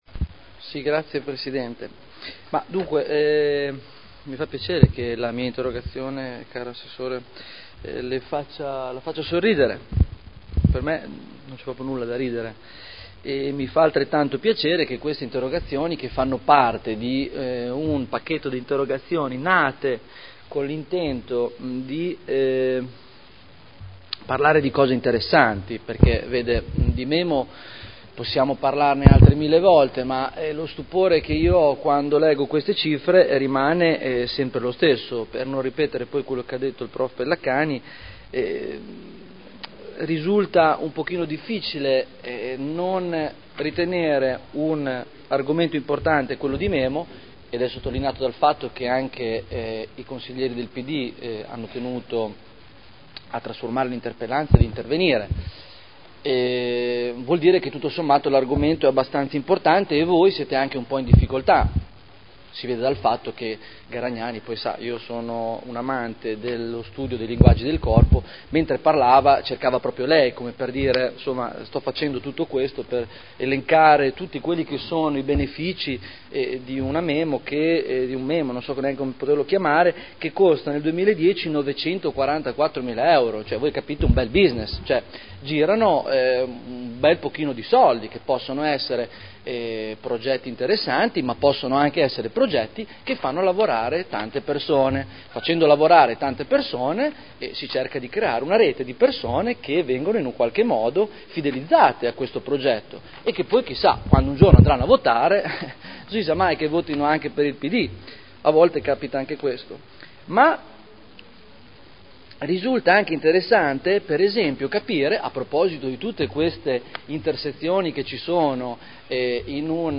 Seduta del 22/12/2011. dibattito sulle 2 Interrogazioni del consigliere Barberini (Lega Nord) avente per oggetto: “MEMO”